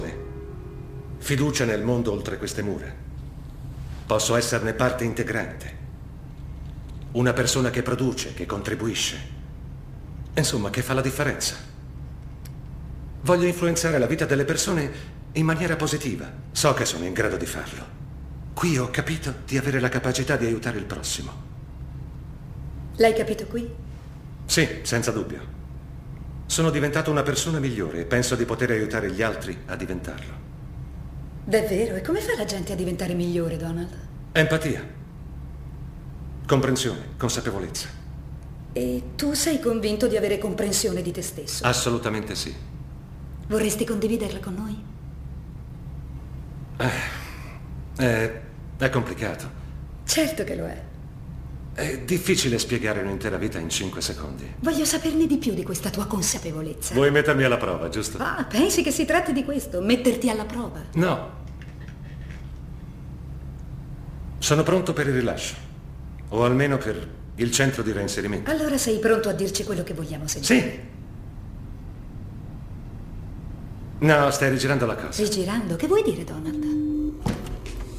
nel film "The Dark Hours", in cui doppia Bruce McFee.